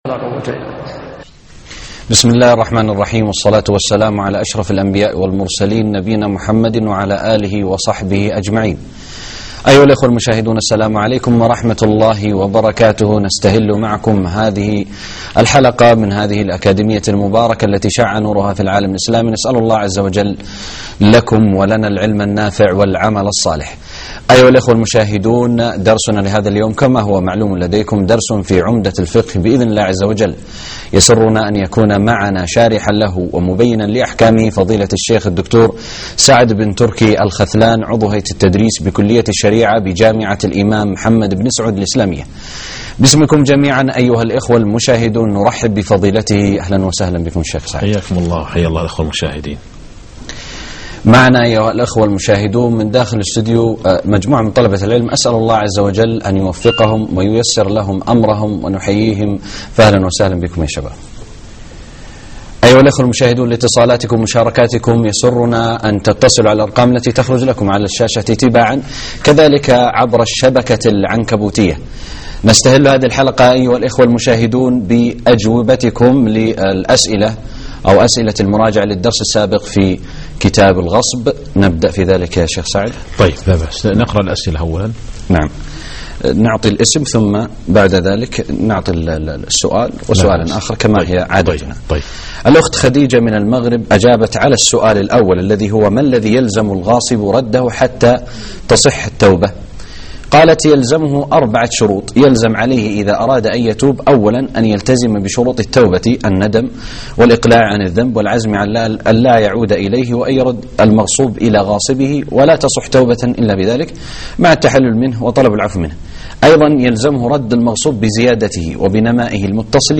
الدرس 18 _ باب الغصب _ المواشي السائبة على الطريق